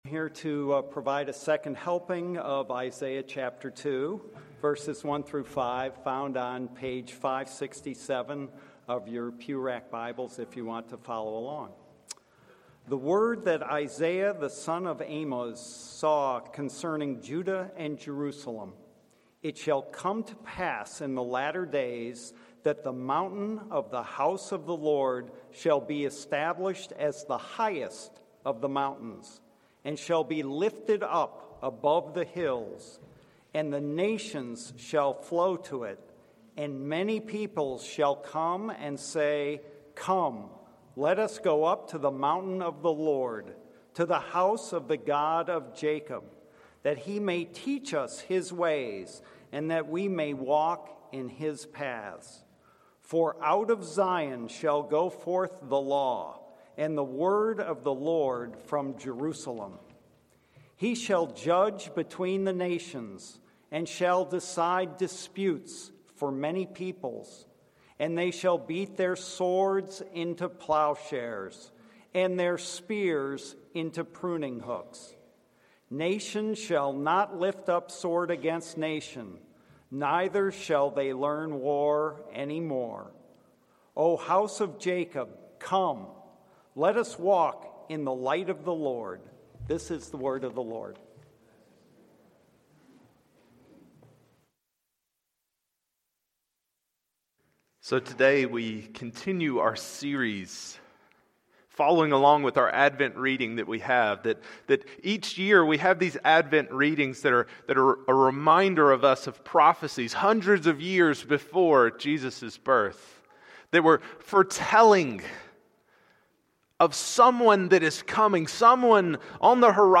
Passage: Isaiah 2:1-5 Sermon